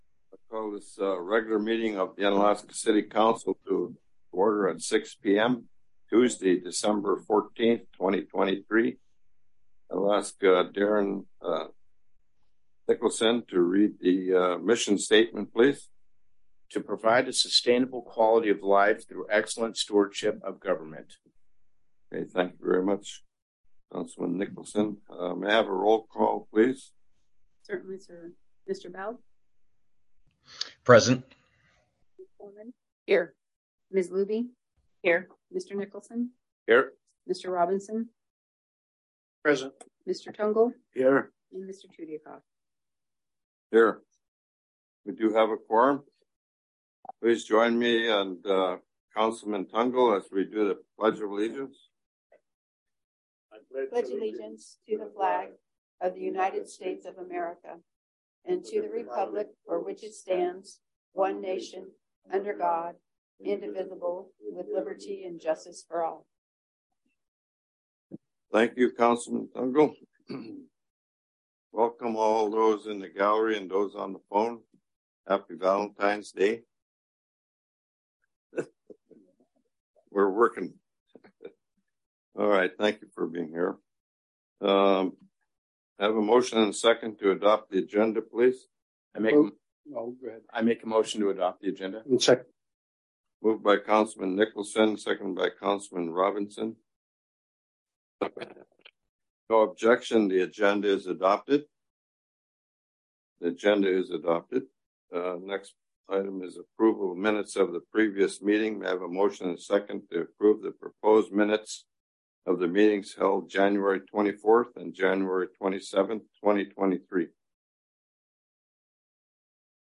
City Council Meeting - February 14, 2023 | City of Unalaska - International Port of Dutch Harbor
In person at City Hall (43 Raven Way)